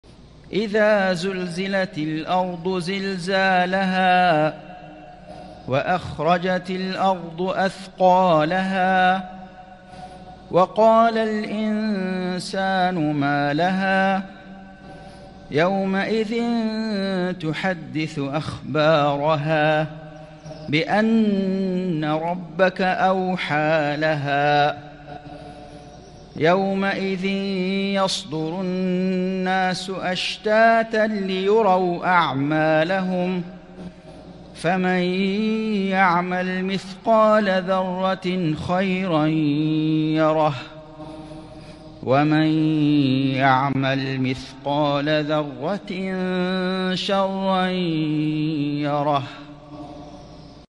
سورة الزلزلة > السور المكتملة للشيخ فيصل غزاوي من الحرم المكي 🕋 > السور المكتملة 🕋 > المزيد - تلاوات الحرمين